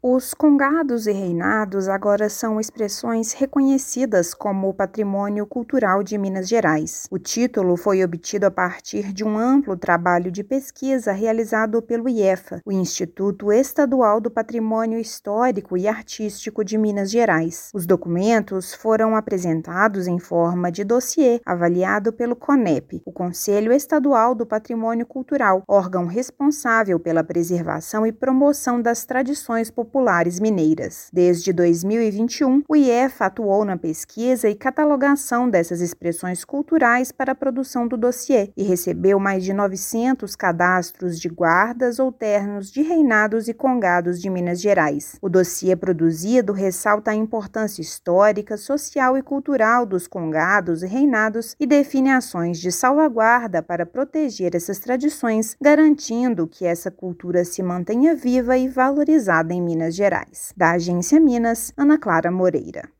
Anúncio realizado durante Festival Cozinha das Afromineiridades, no Palácio da Liberdade, valoriza expressões culturais que compõem a história do povo mineiro. Ouça matéria de rádio.